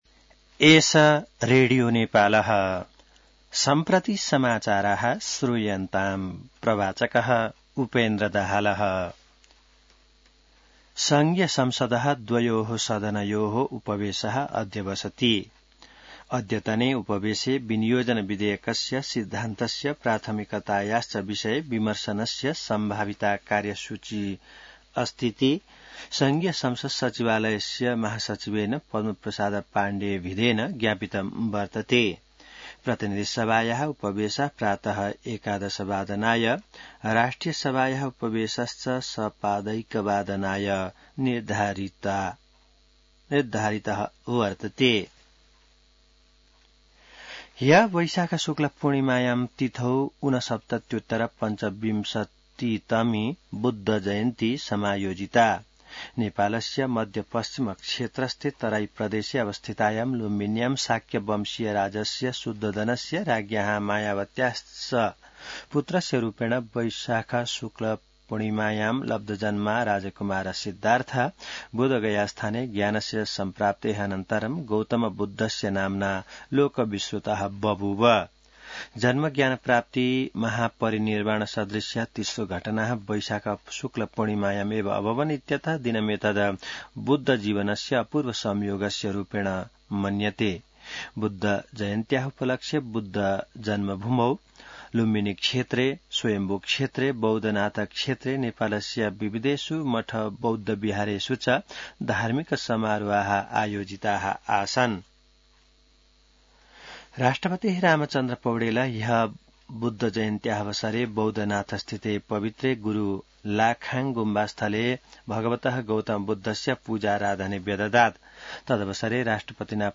संस्कृत समाचार : ३० वैशाख , २०८२